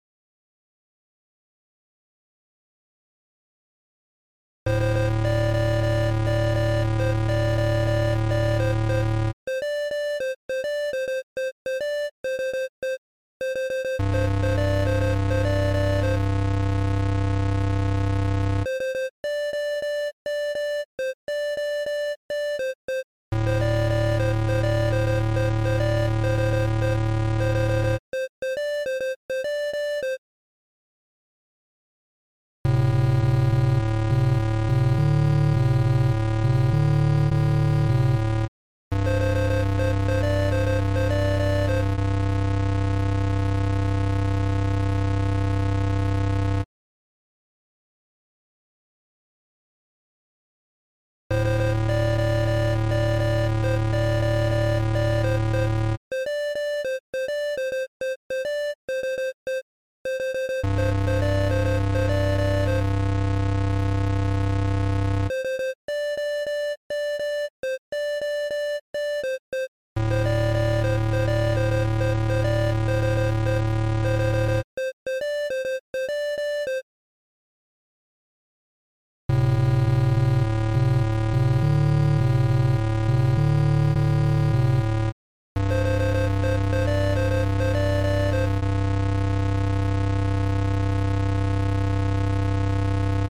posted 9 years ago Writer Playing around with an idea, the notes in this are largely Morse code, with the exception of the base.
Music / Game Music
8-bit chiptune
ambient